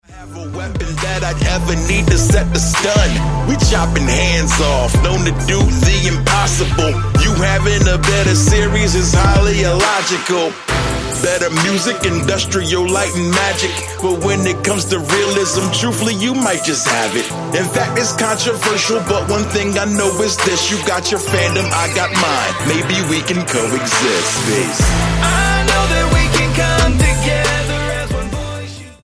STYLE: Hip-Hop
Sung hooks and some clever production